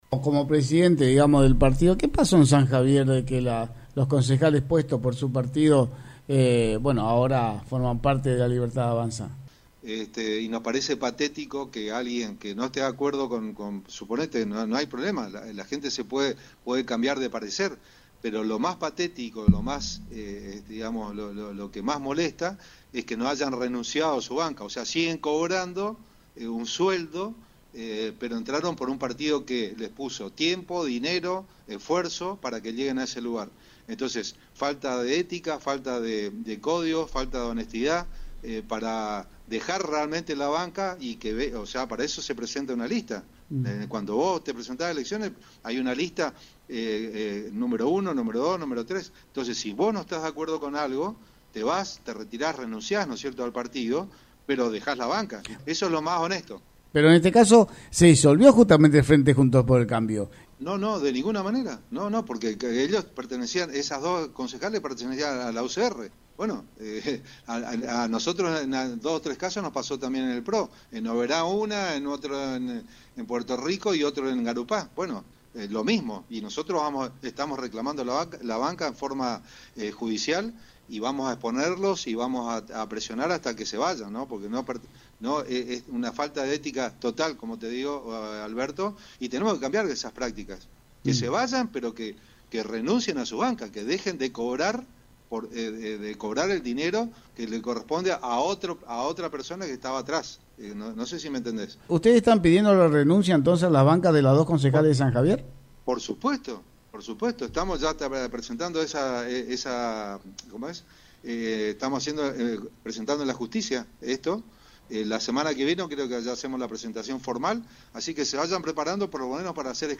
En declaraciones a FM Alto Uruguay